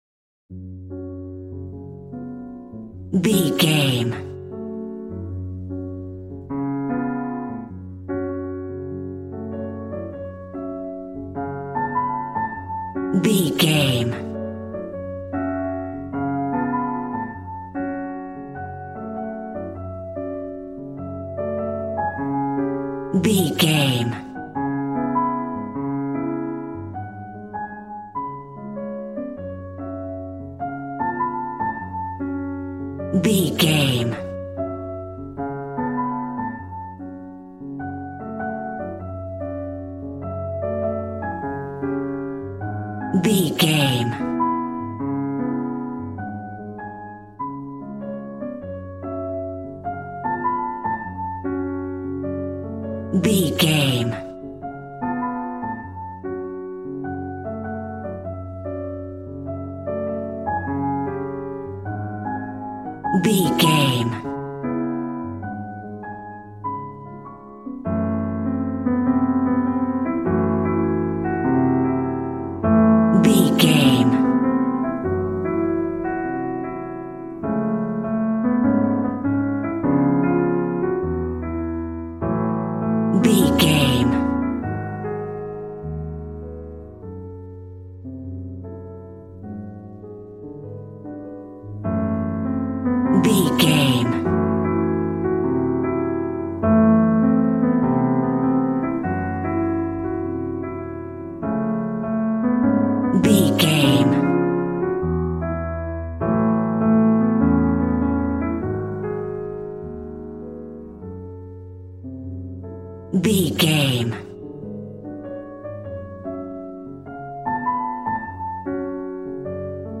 Smooth jazz piano mixed with jazz bass and cool jazz drums.,
Ionian/Major